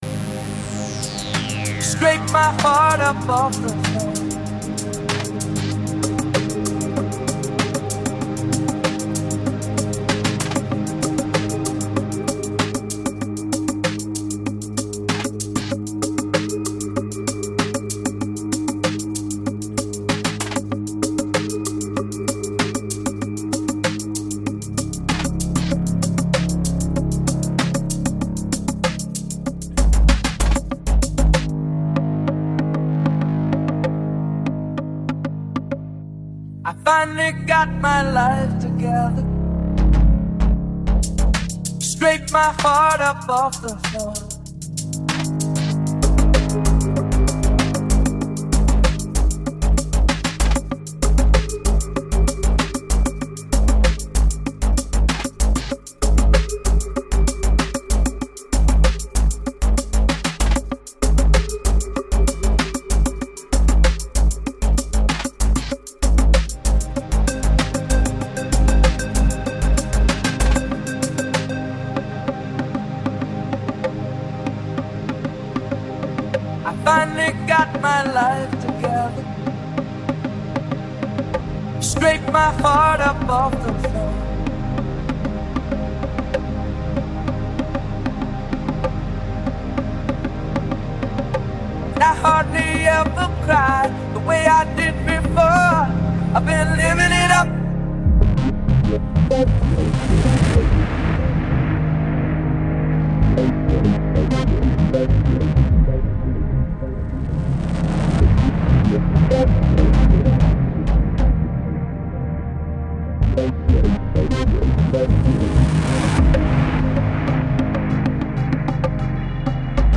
has a dreamy feeling and widescreen synth architecture
Balearic, House